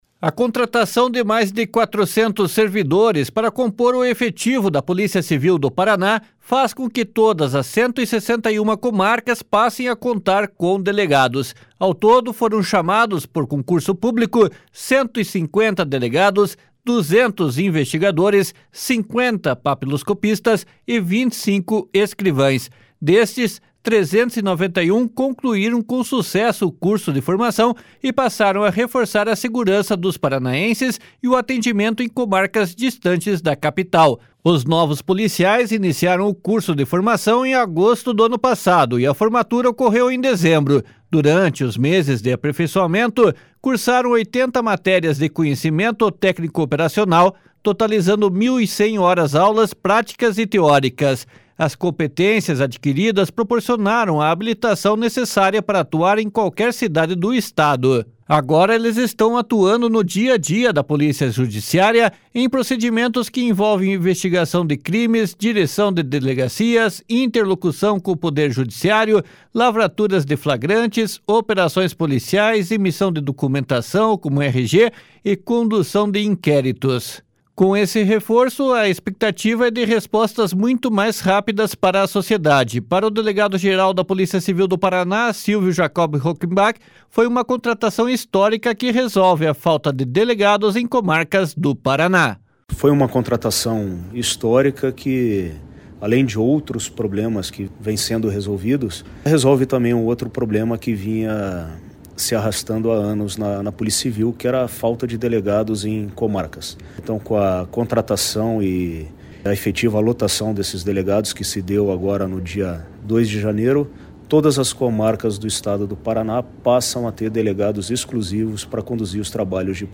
Para o delegado-geral da Polícia Civil do Paraná, Silvio Jacob Rockembach, foi uma contratação histórica que resolve a falta de delegados em comarcas do Paraná. //SONORA SILVIO JACOB ROCKEMBACH//